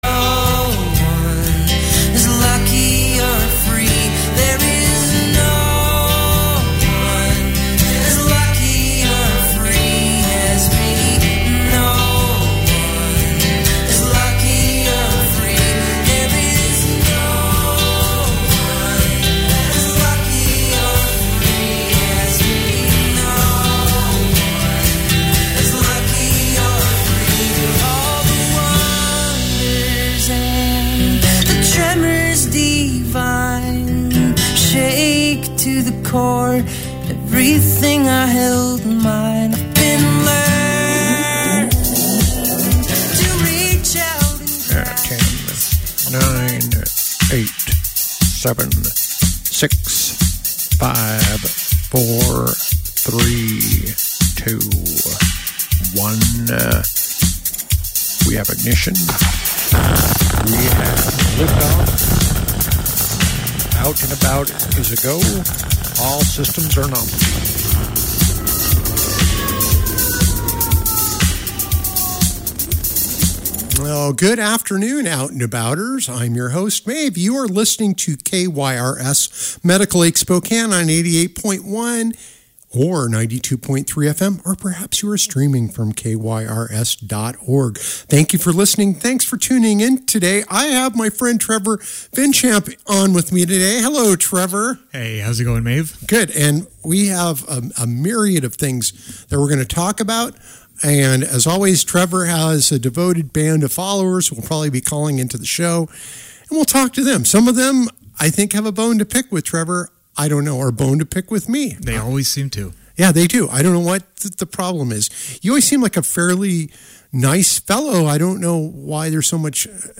Bruce Wayne called in not long after and started to psychoanalyze The Batman.